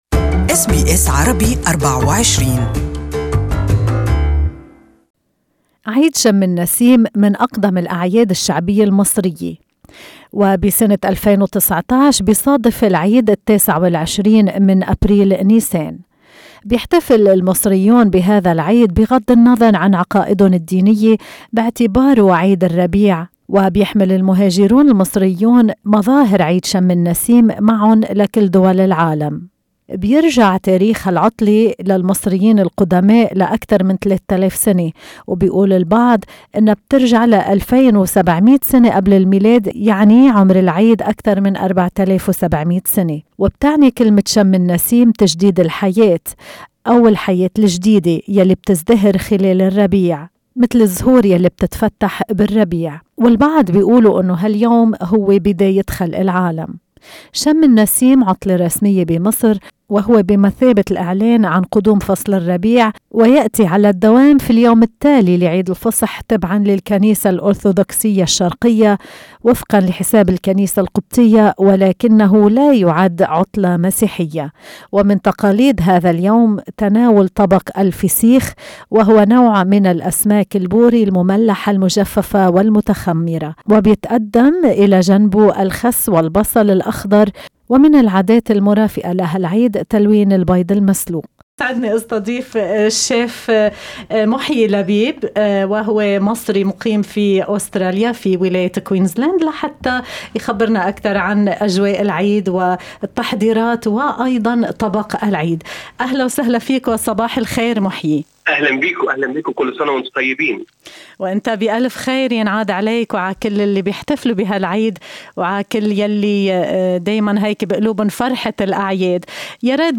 المزيد عن العيد وطريقة تحضير طبق "الفسيخ" في المقابلة الصوتية المرفقة بالصورة.